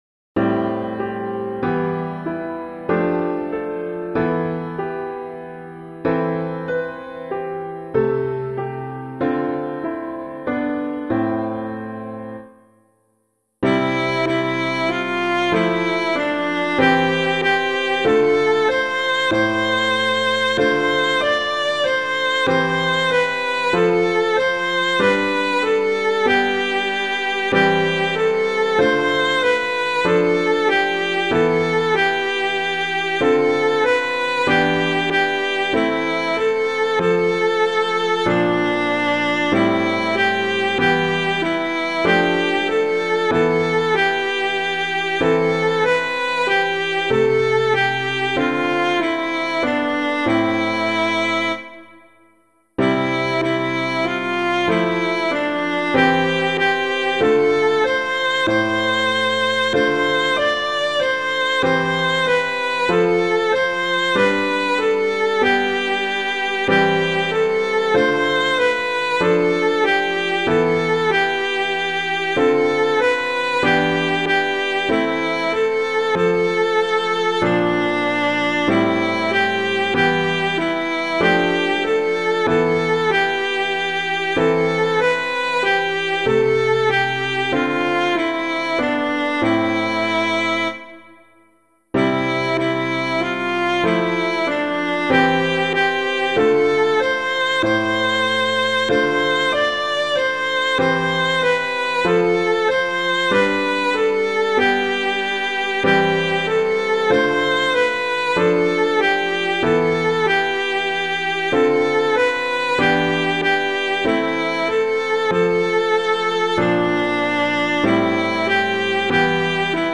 Composer:    Chant, mode III.
piano
Sing My Tongue the Glorious Battle [Neale - PANGE LINGUA] - piano.mp3